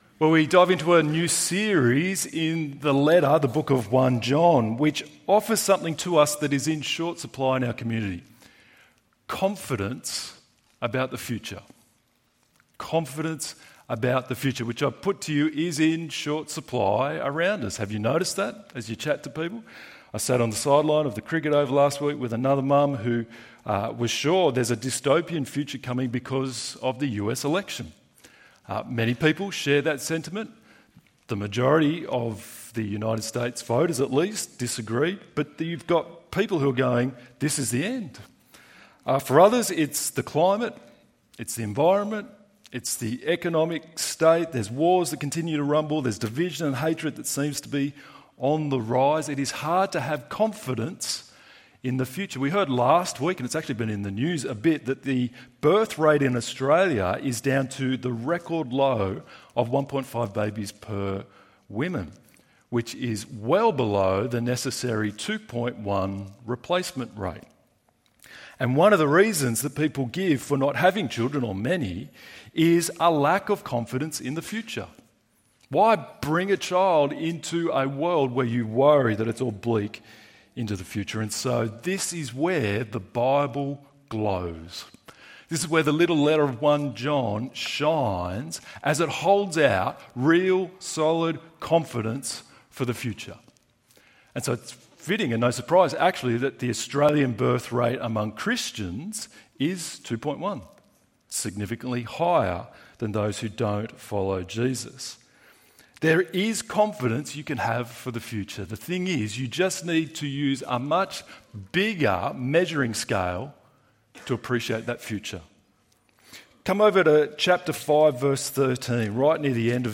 Forgiveness in the Son ~ EV Church Sermons Podcast